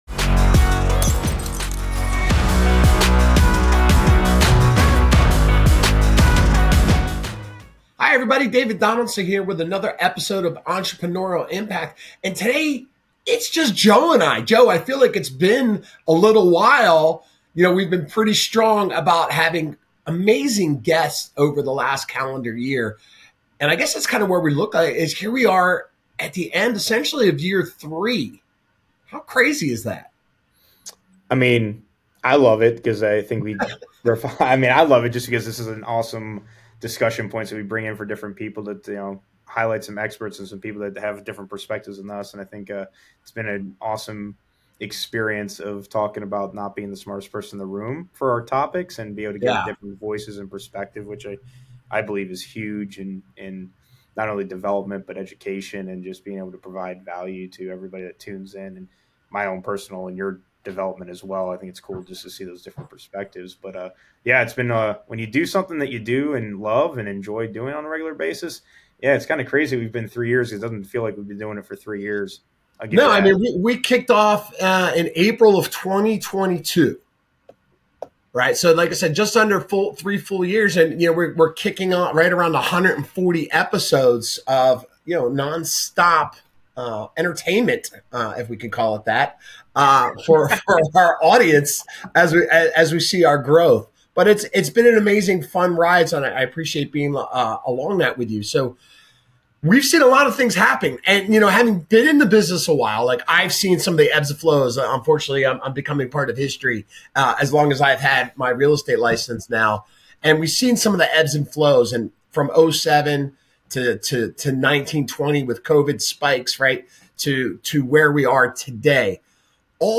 This is a no-holds-barred conversation about adapting to change, defining value in uncertain markets, and building meaningful relationships that drive success.